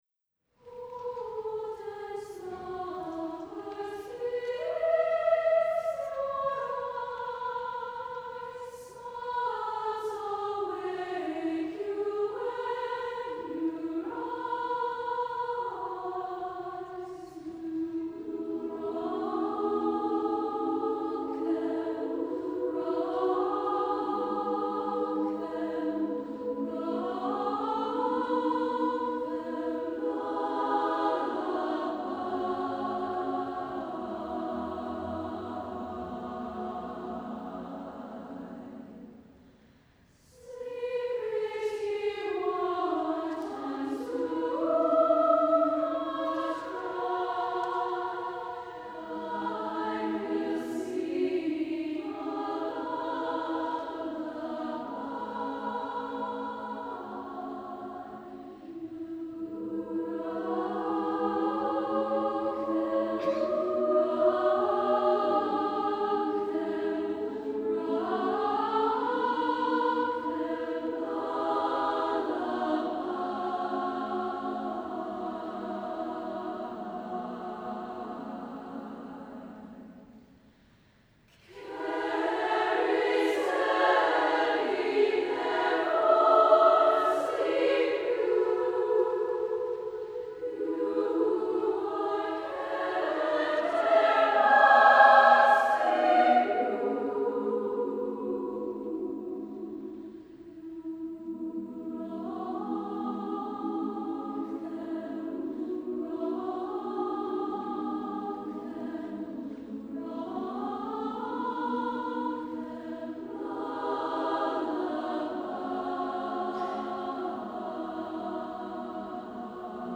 SATB a cappella (also SSAA a cappella)
SSAA: